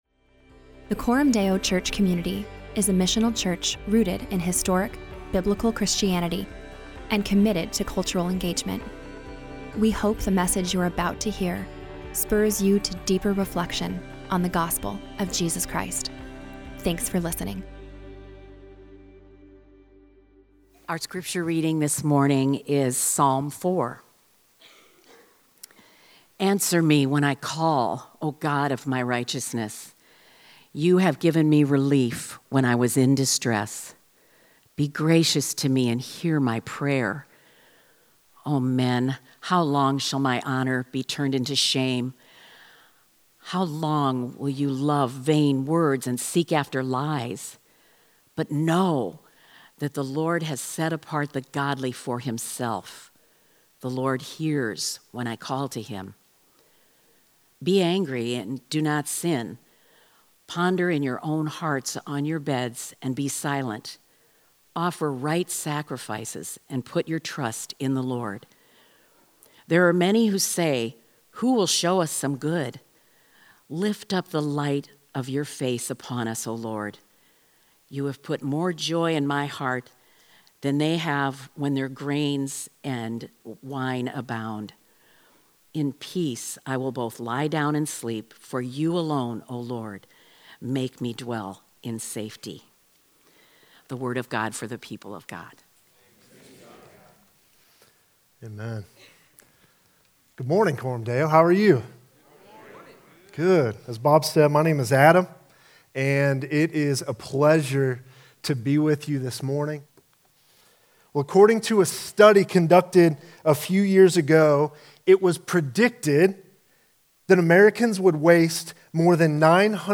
In this sermon, we learn how to cry out to God when it seems like He’s not answering.